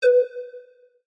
pause-retry-click.ogg